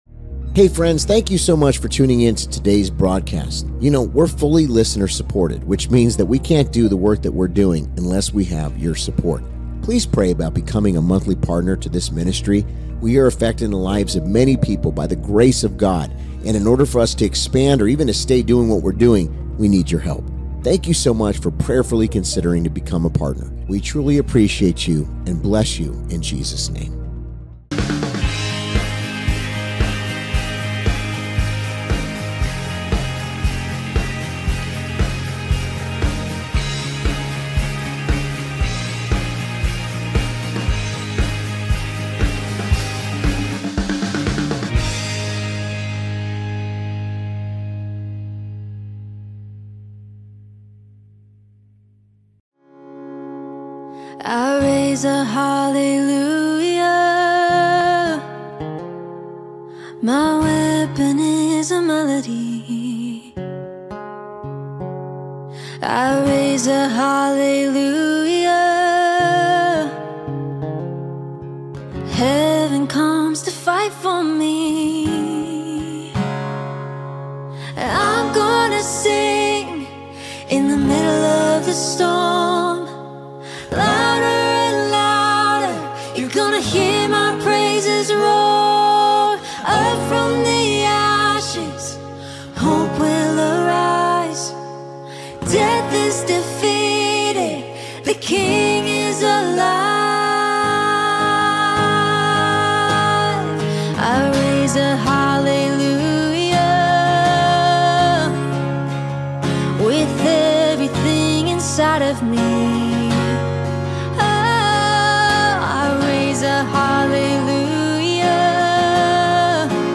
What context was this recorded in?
Sunday Service • "David vs Goliath"